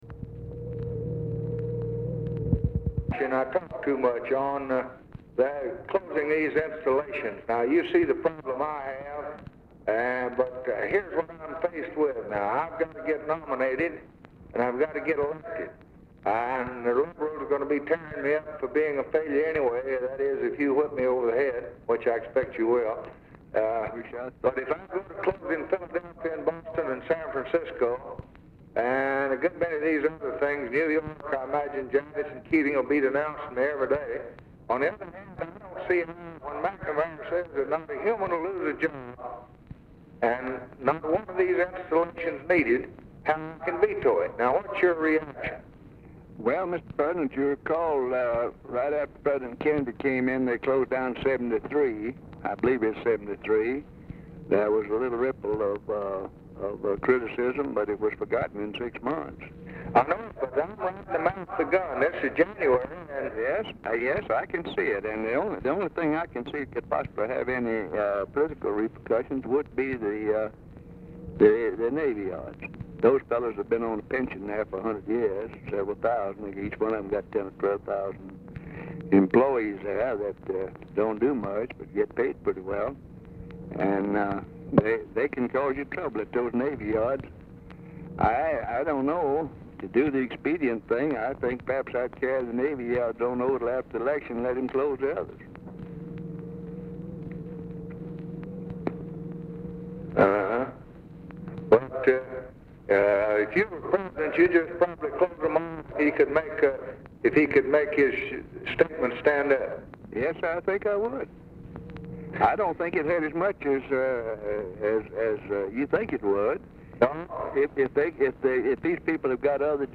Telephone conversation # 335, sound recording, LBJ and RICHARD RUSSELL, 12/7/1963, 5:00PM
RECORDING STARTS AFTER CONVERSATION HAS BEGUN; POOR SOUND QUALITY
Format Dictation belt